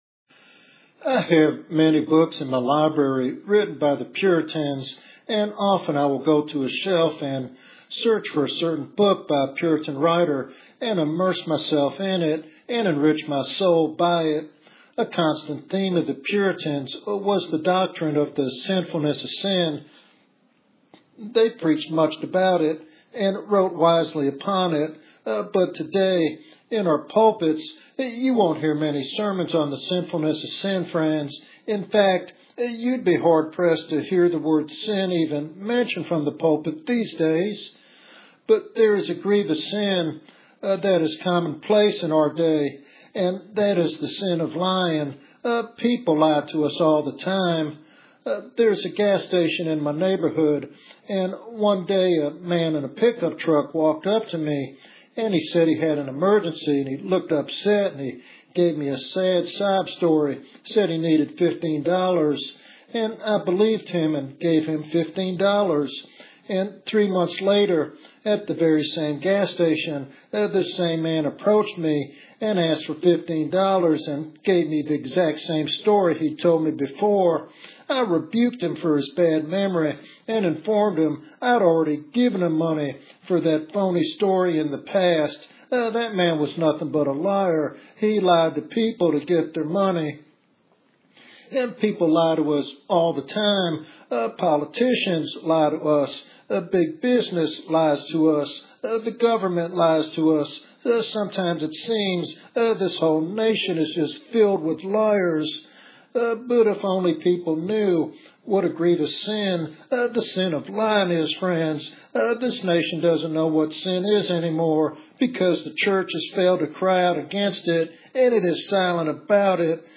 In this compelling sermon